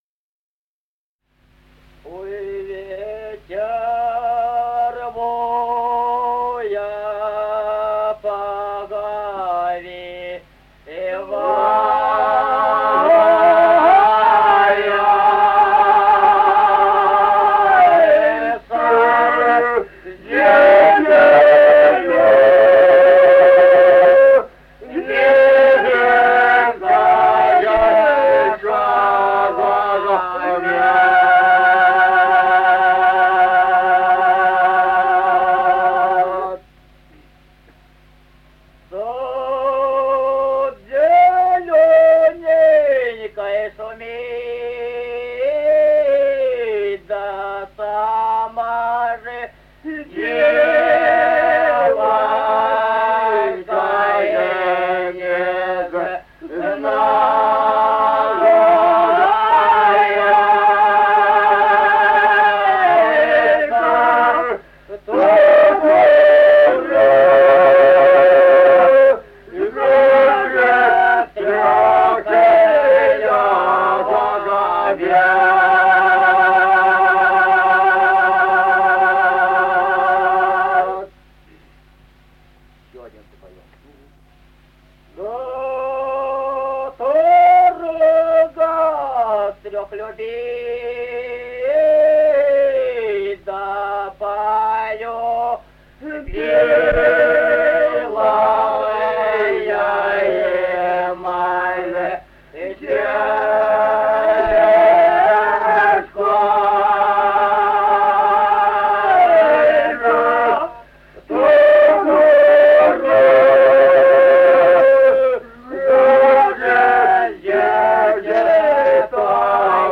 Музыкальный фольклор села Мишковка «Ой, ветер воя, повевая», тюремная.